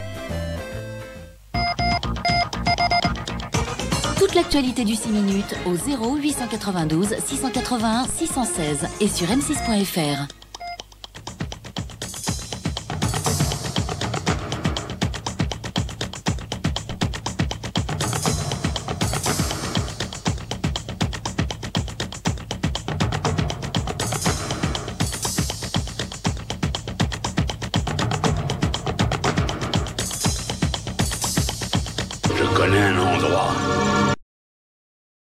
Long tapis sonore 6 minutes 05/04/2004 (Rennes)